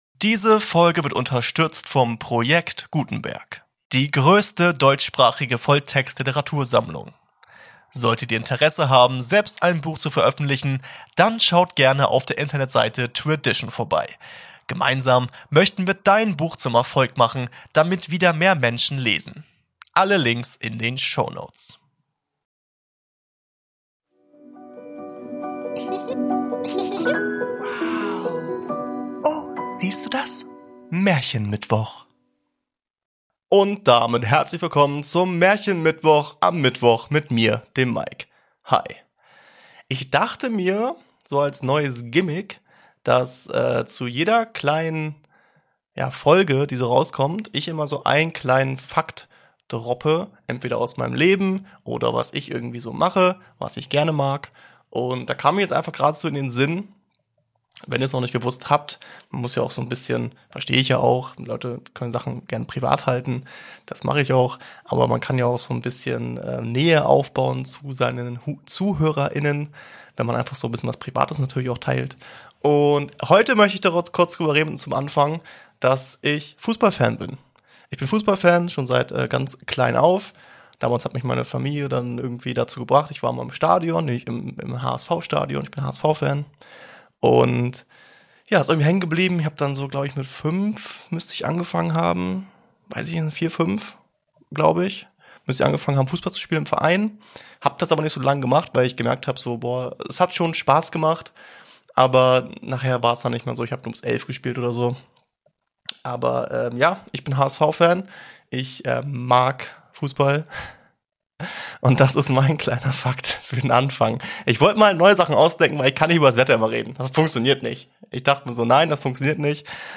Music Intro -